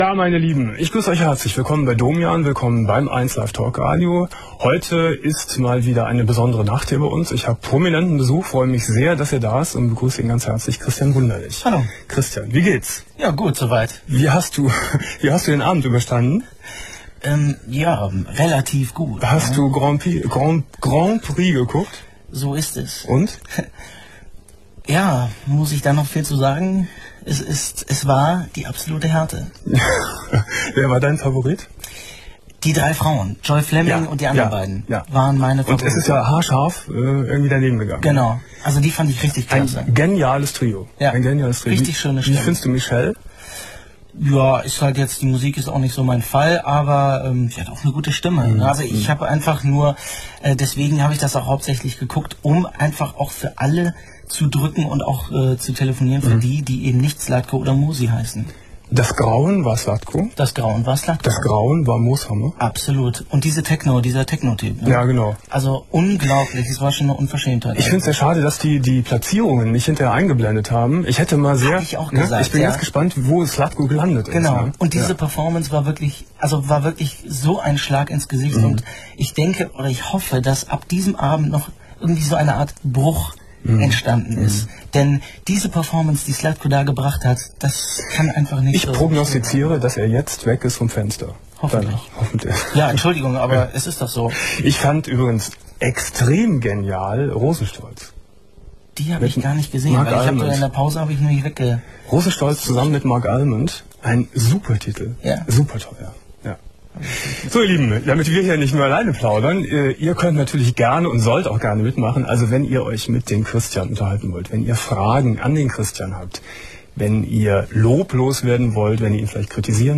03.03.2001 Domian Studiogast: Christian Wunderlich ~ Domian Talkradio - Das Archiv Podcast